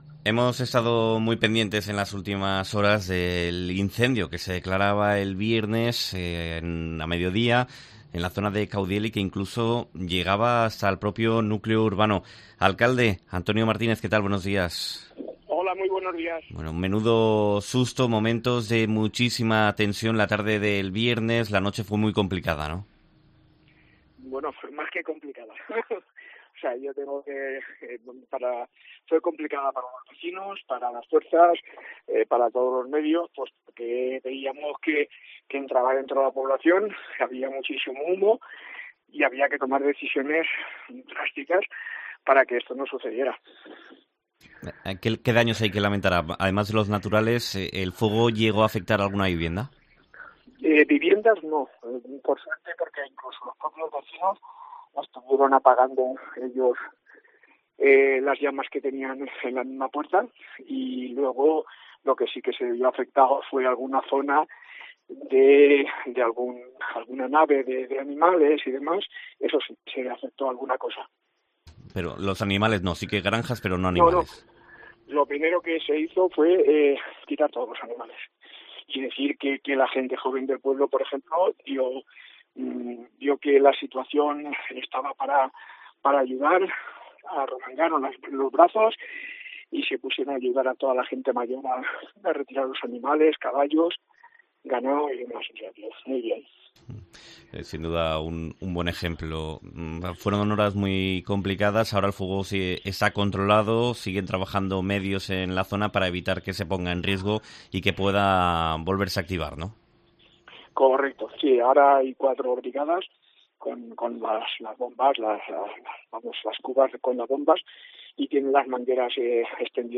Entrevista
Caudiel denuncia el incendio forestal causado por un tren, como explica en COPE el alcalde, Antonio Martínez